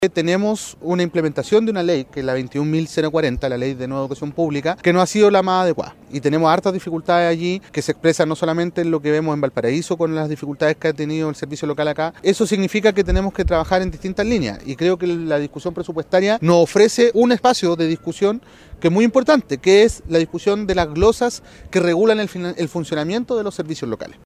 Cabe destacar que los dichos del ministro se dieron en el marco de su visita al Parque Cultural Valparaíso, en el Encuentro Educación: Urgencia Compartida.